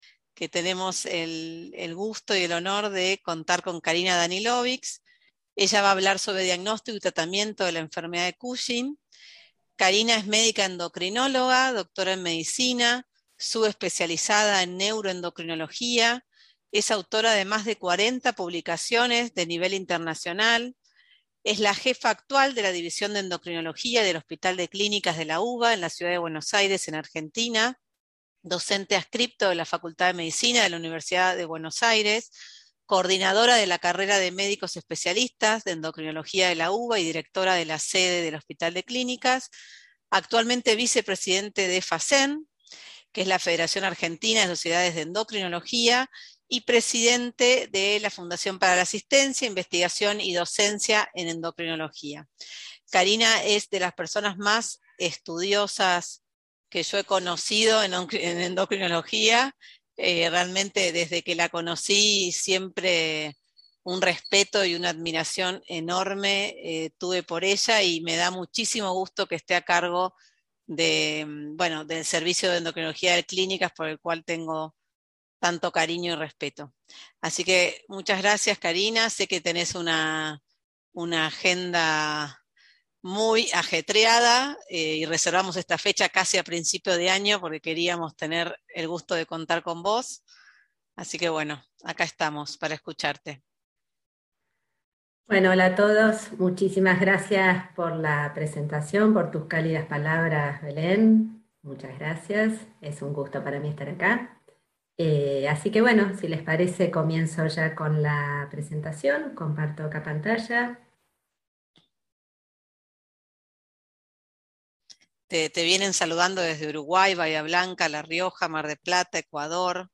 Ateneos médicos
Incluye preguntas y respuestas